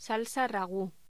Locución: Salsa ragù
voz
Sonidos: Voz humana